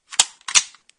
手枪换弹.mp3